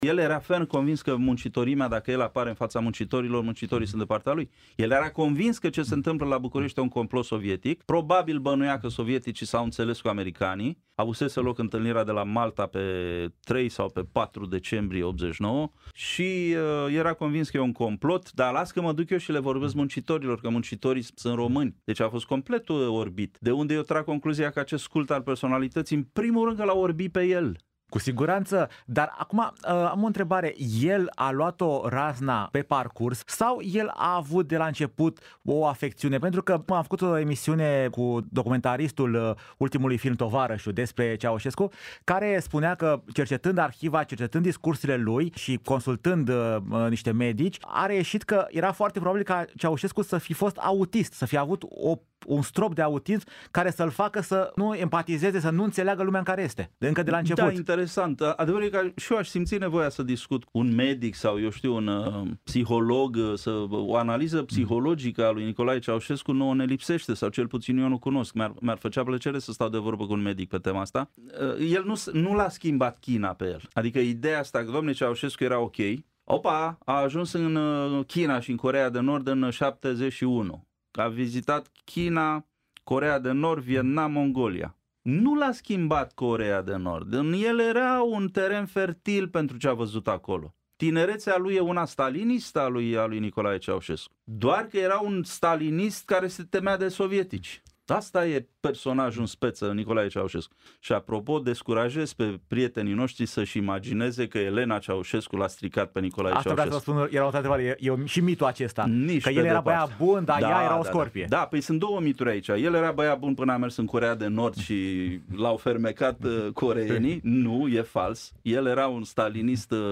La toate aceste întrebări a răspuns istoricul Adrian Cioroianu la Călătorii în trecut.